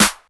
snr_60.wav